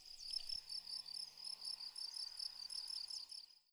Cricket 1.wav